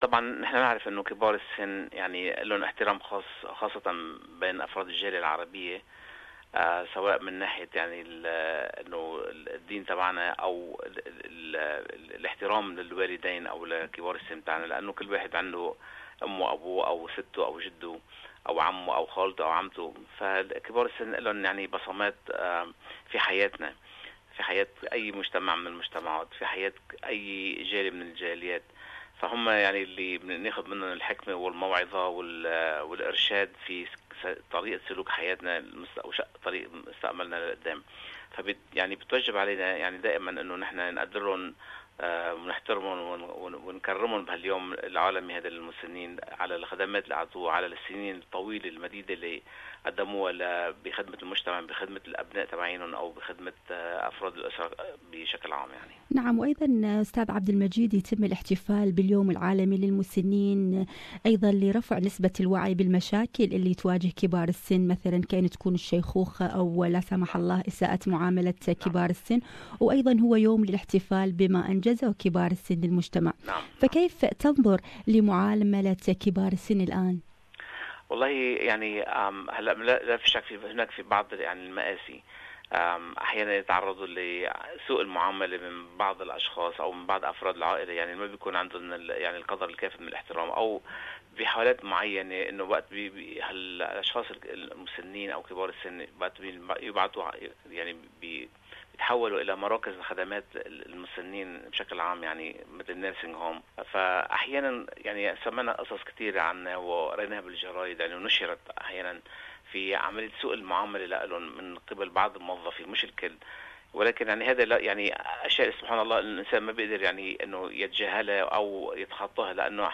This years day is about enabling and expanding the contributions of older people in their families, communities and societies at large. It focuses on the pathways that support full and effective participation in old age, in accordance with old persons basic rights, needs and preferences. More on this issue, listen to this interview